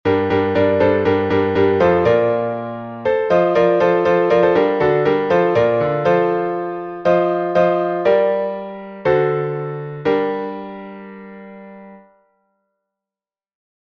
Глас 6